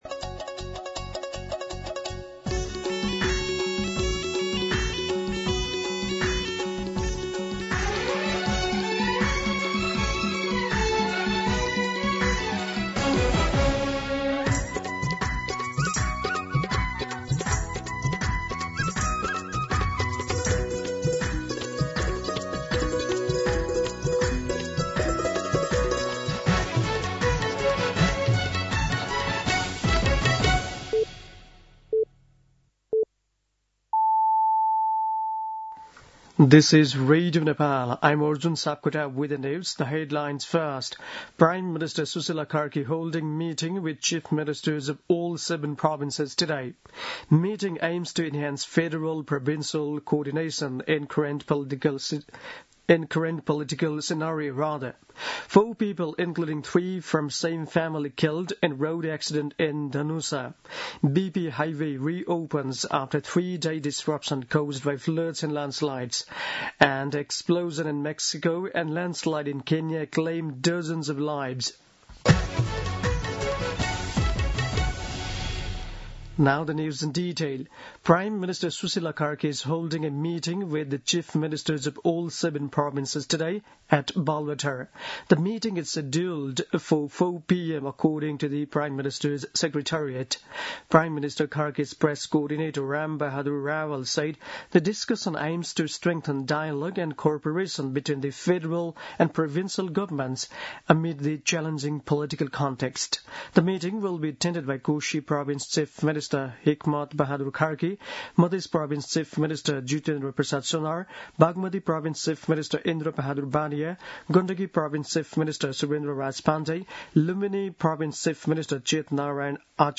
दिउँसो २ बजेको अङ्ग्रेजी समाचार : १६ कार्तिक , २०८२
2-pm-English-News.mp3